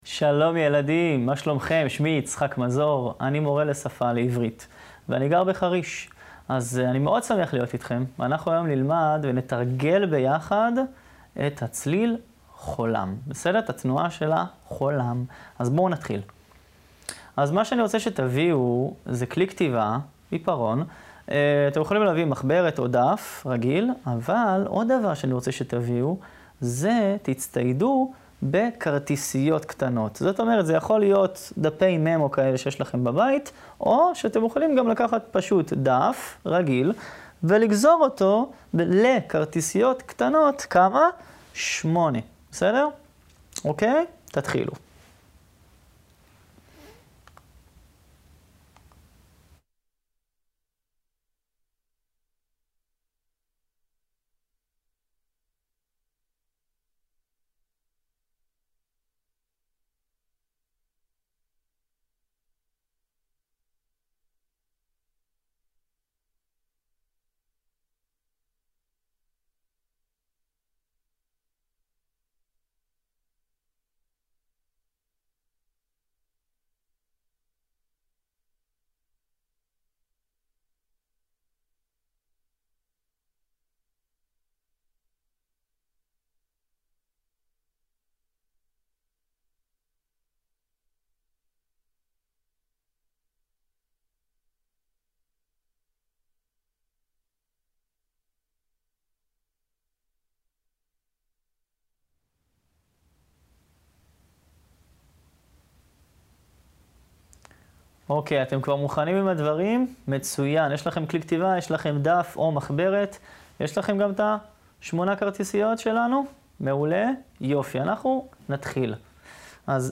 קריאת הסיפור "לדעת לקרוא" מתוך ילדותנו לכתה א, איתור מידע, יחידה רבות, מאזכרים, השוואה